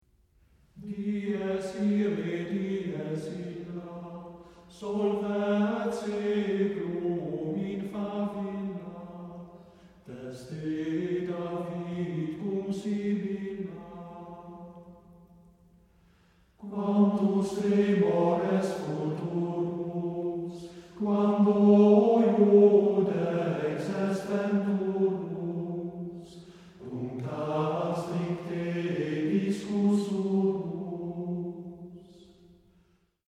Dies irae (gregoriaanse zang)